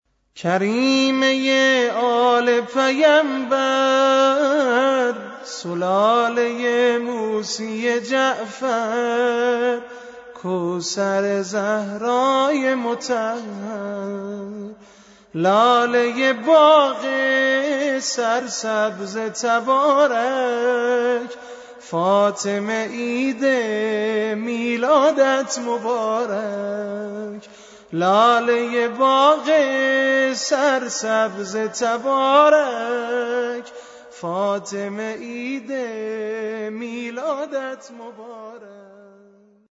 سرود غلامرضا سازگار